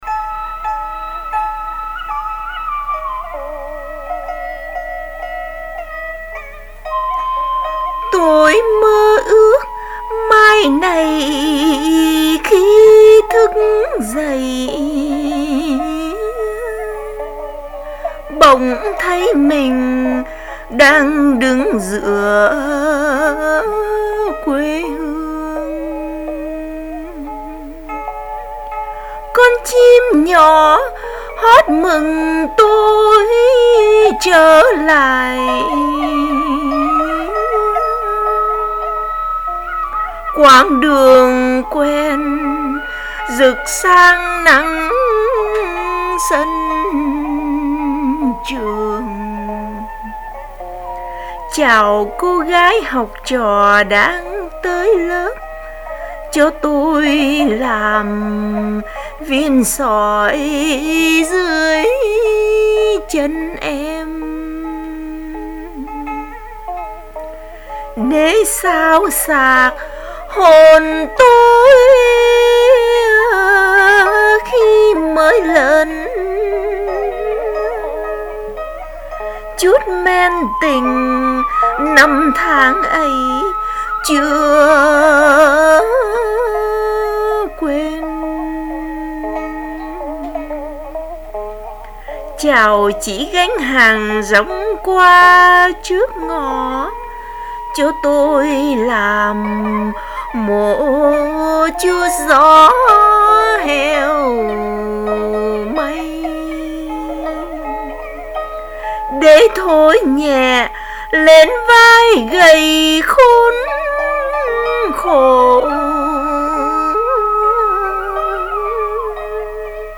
Ngâm
NgamThoGiacMoNhoCuaToi.mp3